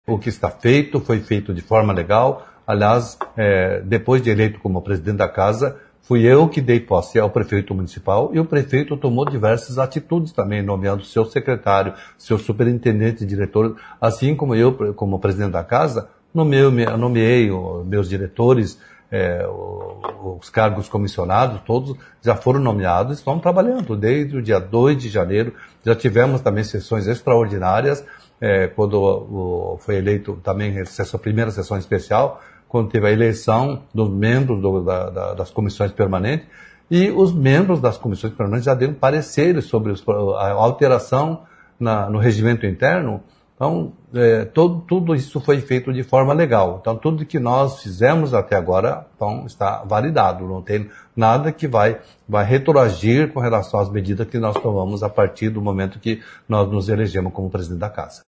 Os atos legislativos de Hossokawa seriam anulados? O vereador também falou sobre isso: